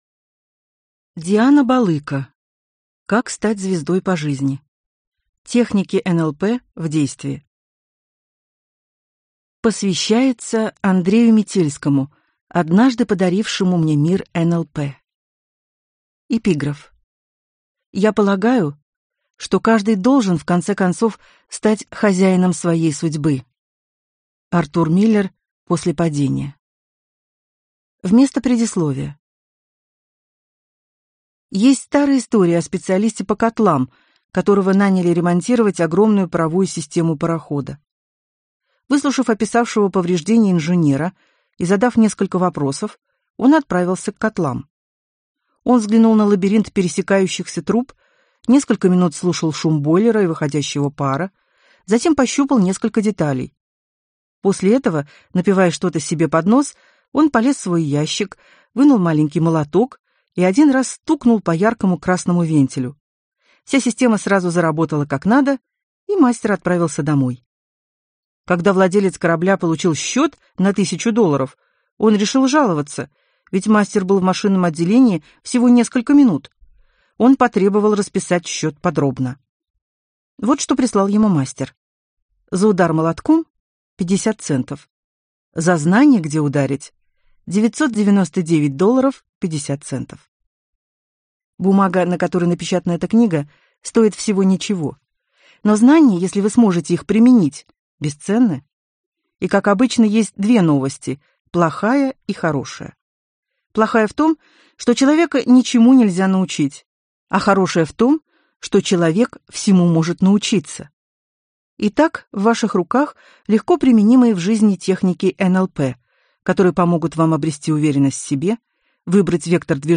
Аудиокнига Как стать звездой по жизни. Техники НЛП в действии | Библиотека аудиокниг